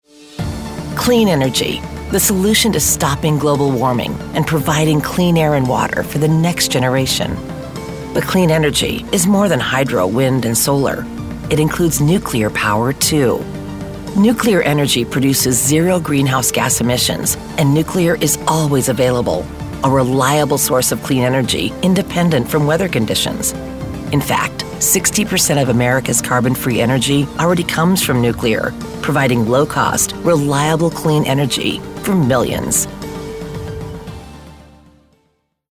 Confident and sultry with a raspy timbre that is very reassuring.
anti-announcer, authoritative, compelling, professional